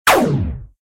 laser-fire.ogg